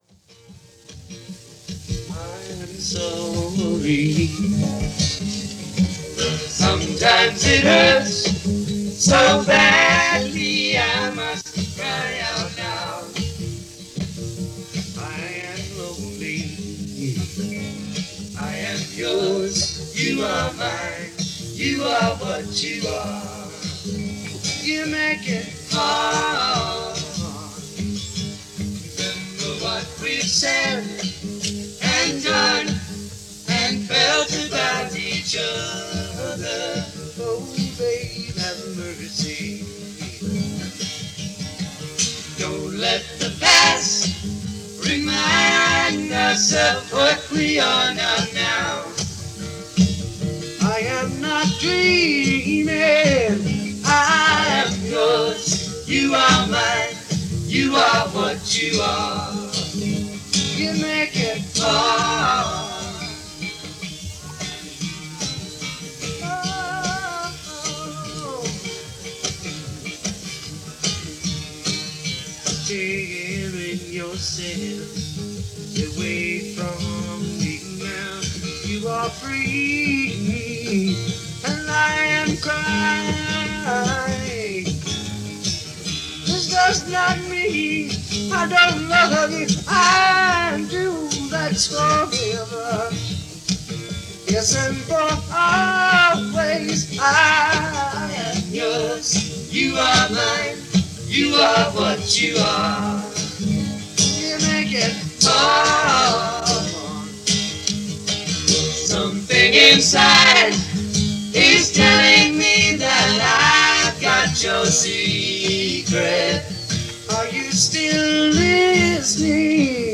recorded in concert on August 26, 1969
Recorded at The Greek Theatre in L.A. on August 26, 1969.